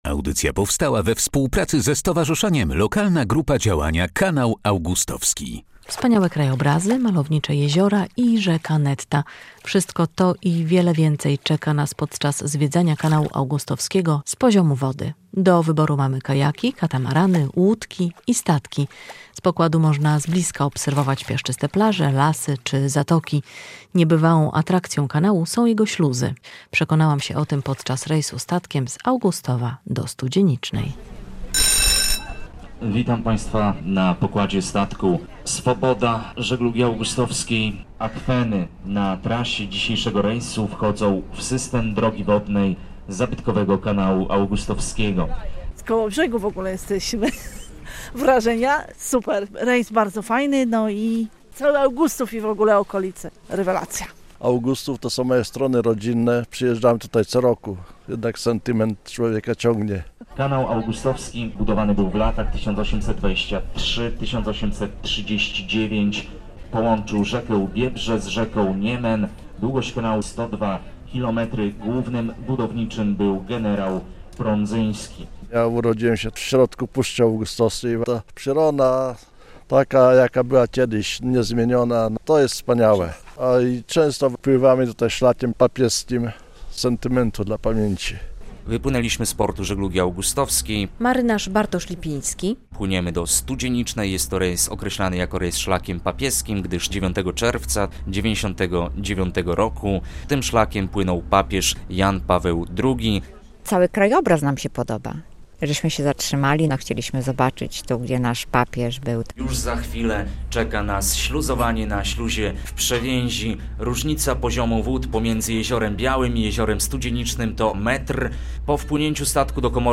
Na pokładzie statku Żeglugi Augustowskiej